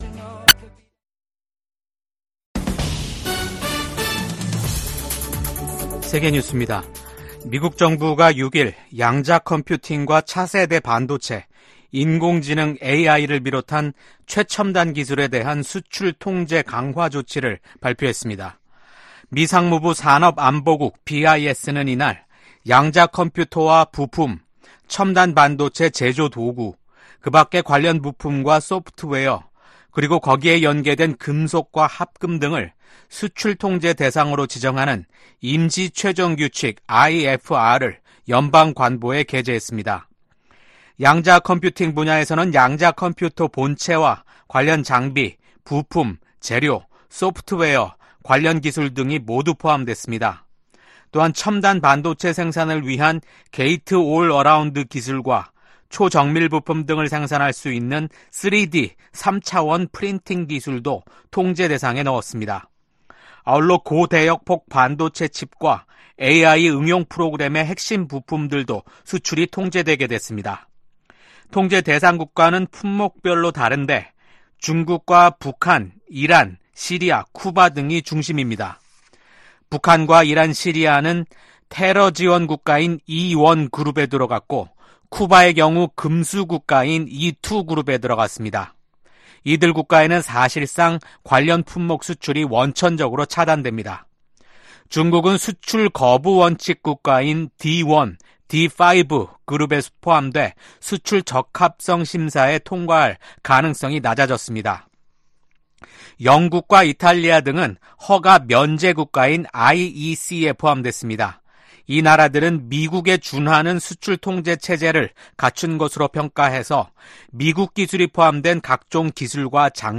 VOA 한국어 아침 뉴스 프로그램 '워싱턴 뉴스 광장' 2024년 9월 7일 방송입니다. 윤석열 한국 대통령은 퇴임을 앞두고 방한한 기시다 후미오 일본 총리와 정상회담을 가졌습니다. 이에 대해 미국 국무부는 미한일 3국 관계가 공동 안보와 이익에 매우 중요하다고 강조했습니다. 미한 양국이 북한 핵 위협에 대비한 시나리오를 고위급 회담인 확장억제전략협의체 회의에서 논의한 것은 중대한 진전이라는 전문가들의 평가가 나왔습니다.